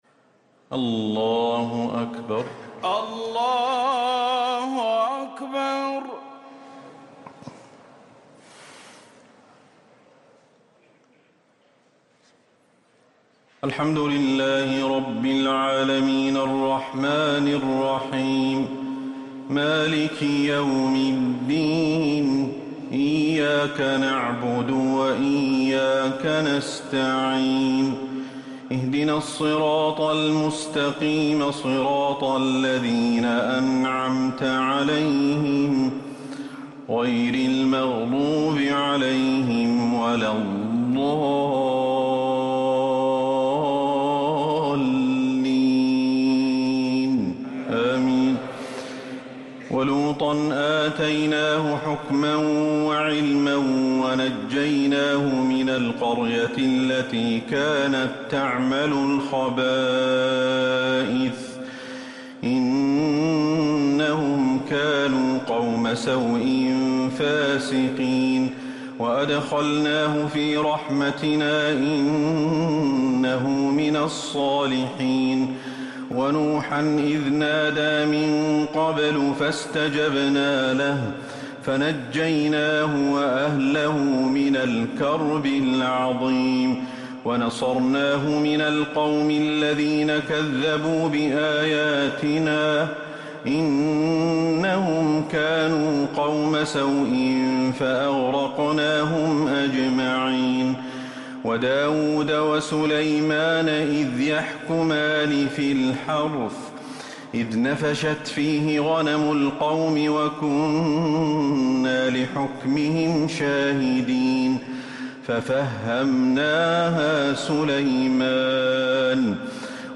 تهجد ليلة 21 رمضان 1444هـ من سورتي الأنبياء (74-112) و الحج (1-24) | Tahajjud 21st night Ramadan 1444H Surah Al-Anbiya and Al-Hajj > رمضان 1444هـ > التراويح - تلاوات الشيخ أحمد الحذيفي